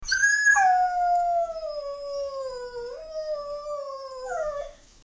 Dog Cry Sound Button - Free Download & Play